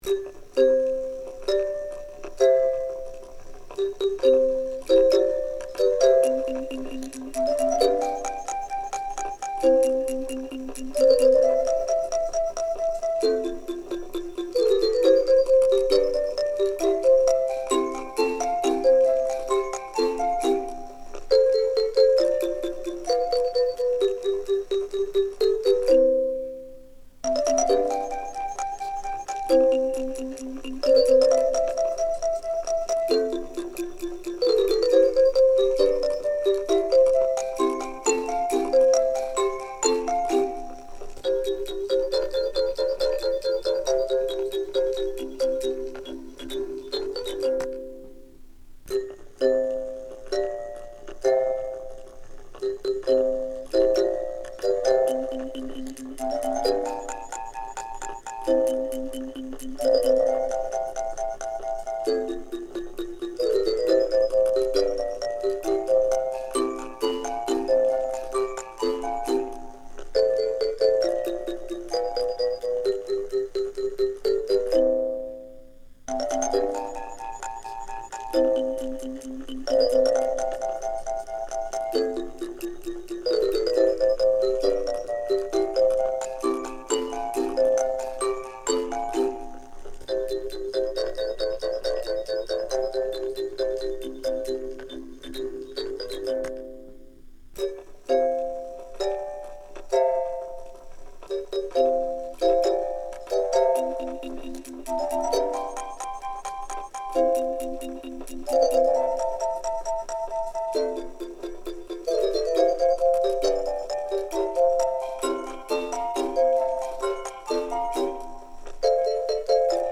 ビデオとパフォーマンスのサウンドトラックとしてつくったものです。
オルゴール